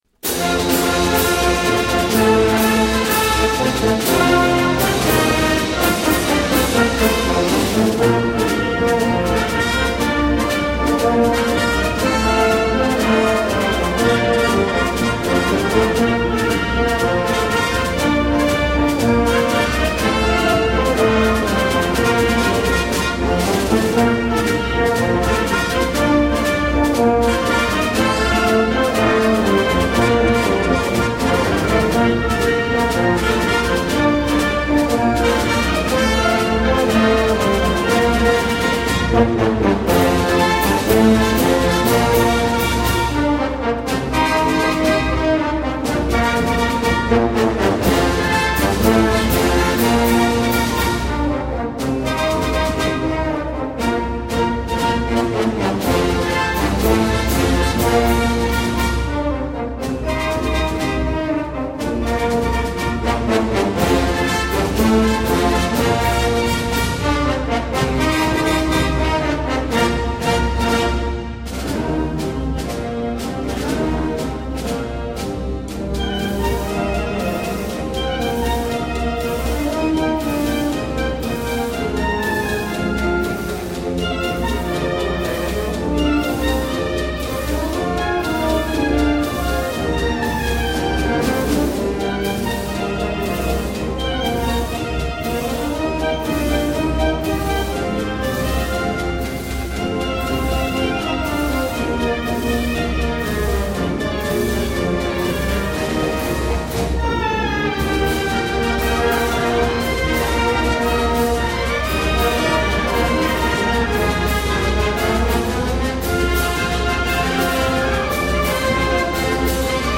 Arianna-Marcia-caratteristica-Creatore.mp3